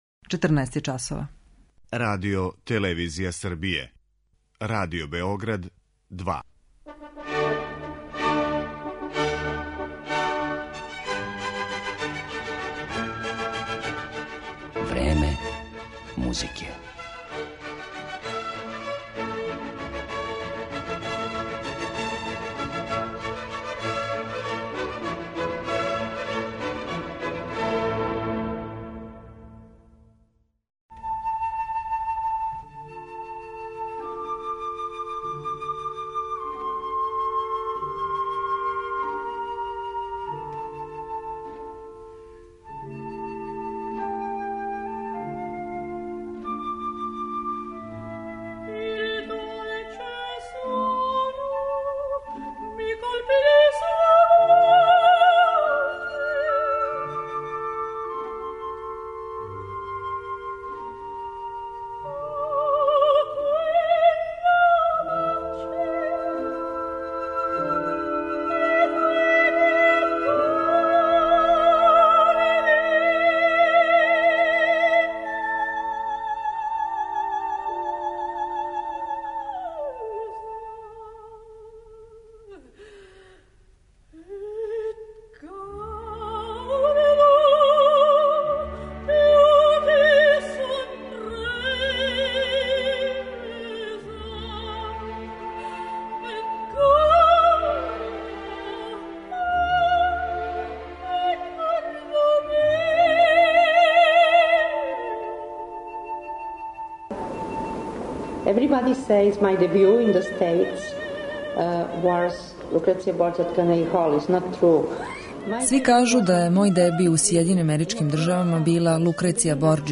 Једном од највећих сопрана нашег доба - Монсерат Кабаје - посвећена је данашња емисија Време музике, у којој ће бити емитован и интервју остварен са уметницом приликом jeдног од њених гостовања код нас.